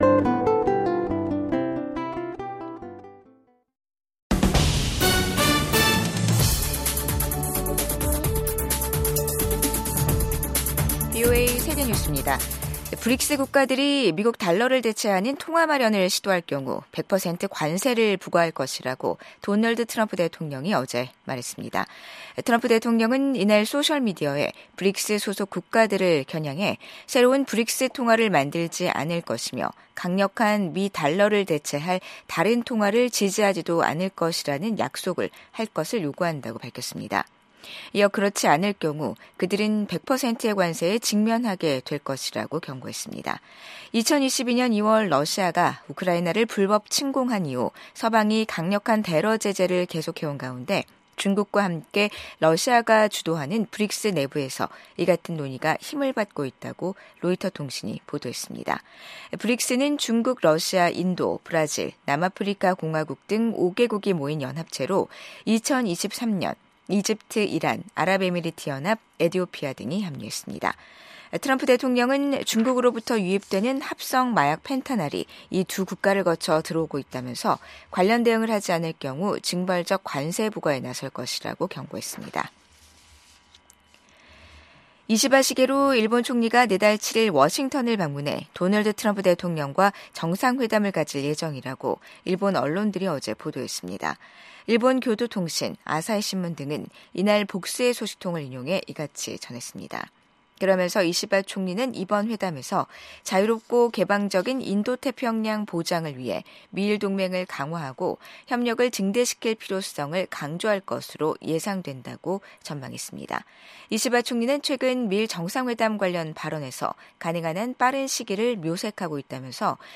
VOA 한국어 간판 뉴스 프로그램 '뉴스 투데이', 2025년 1월 31일 2부 방송입니다. 트럼프 2기 행정부 국가정보국장으로 지명된 털시 개버드 전 하원의원은 미국이 북한의 핵과 미사일 위협을 낮추는 데 대북 정책의 우선순위를 둬야 한다고 밝혔습니다. 미국 육군장관 지명자는 북한, 중국, 러시아, 이란이 미국에 맞서 협력하고 있다고 지적했습니다.